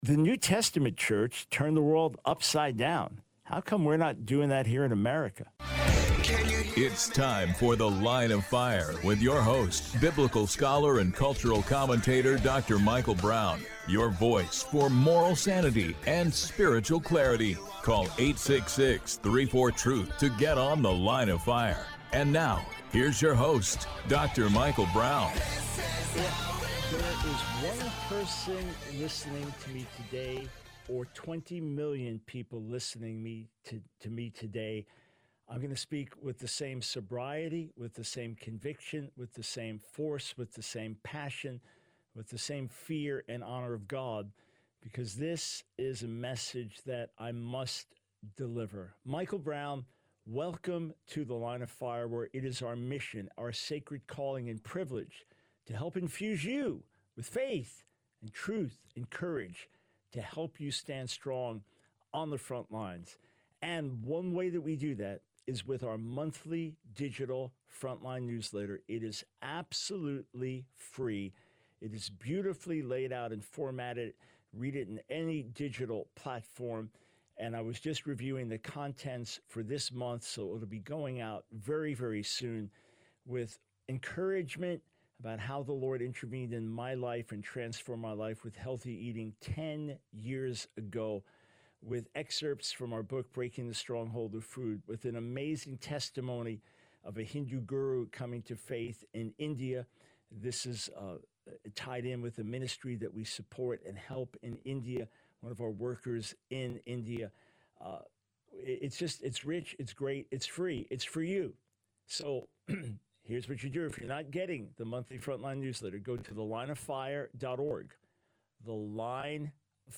The Line of Fire Radio Broadcast for 08/13/24.